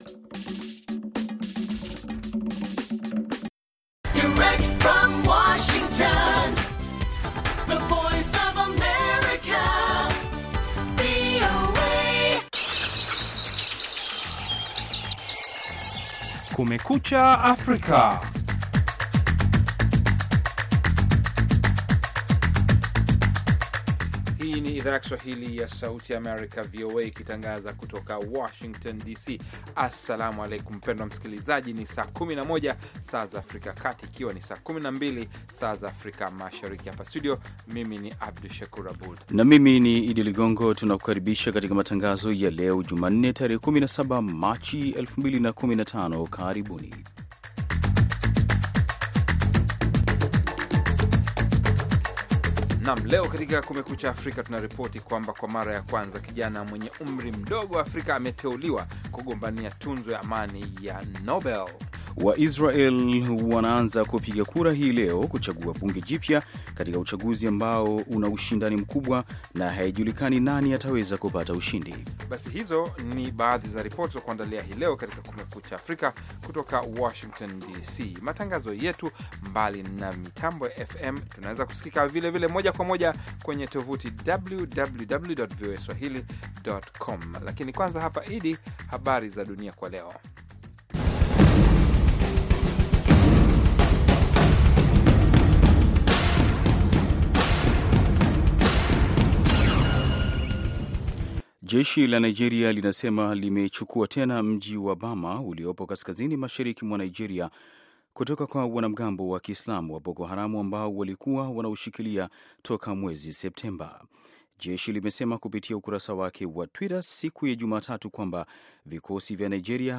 Matangazo ya nusu saa kuhusu habari za mapema asubuhi pamoja na habari za michezo.